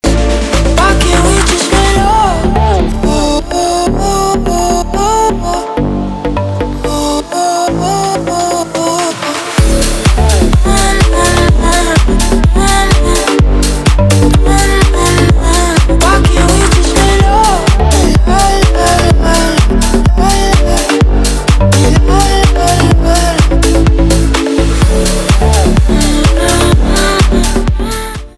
танцевальные
битовые , басы , качающие , кайфовые
грустные